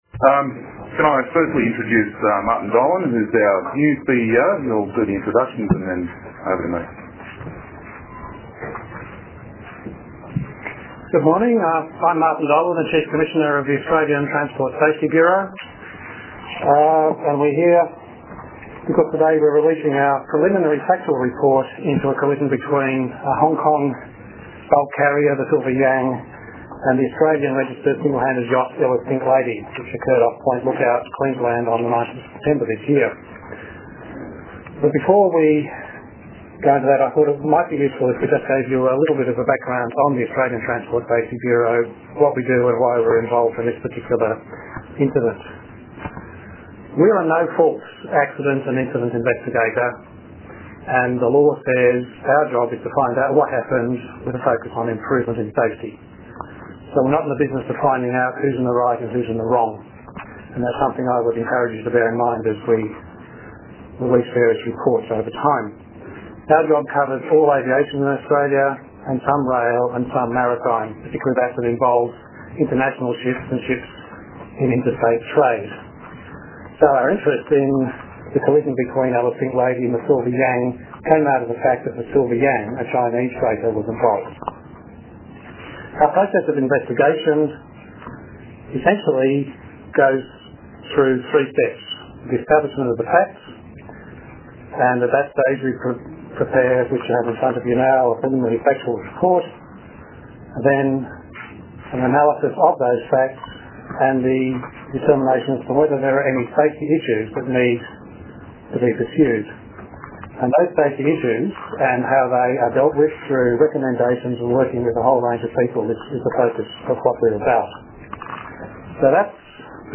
mediaconference.mp3